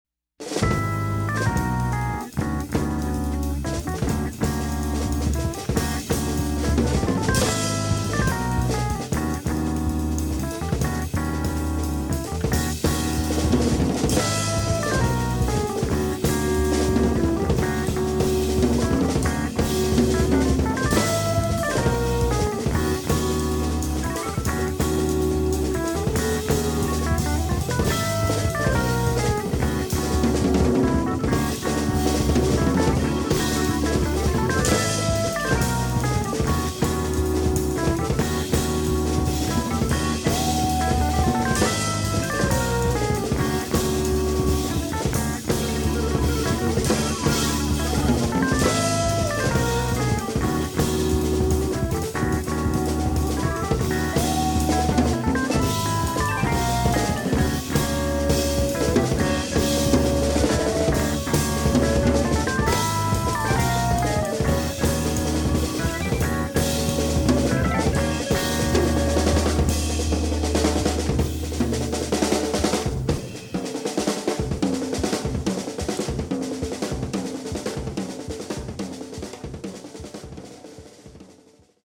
Drums
Acoustic and electric pianos and Moog synthesizer
Acoustic and electric basses
Recorded in 1975